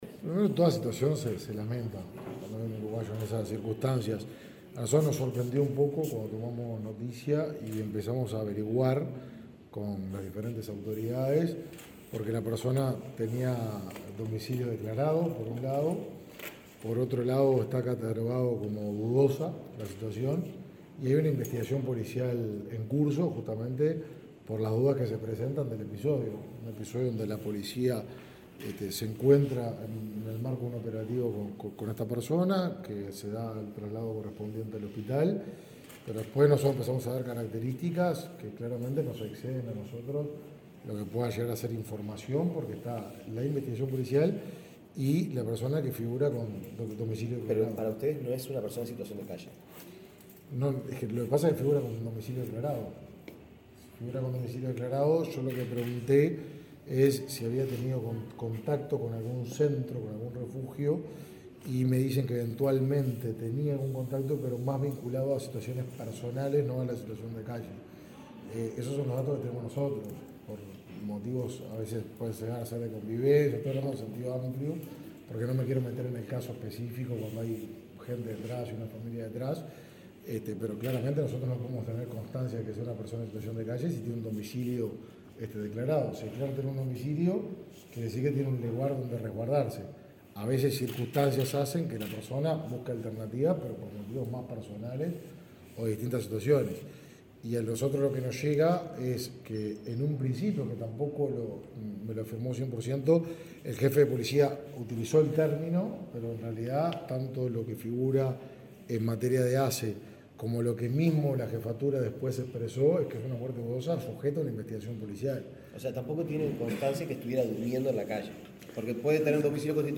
Declaraciones a la prensa del ministro de Desarrollo Social, Martín lema
El ministro de Desarrollo Social, Martín Lema, dialogó con la prensa, tras firmar un convenio laboral con el Grupo SVA, de servicios médicos.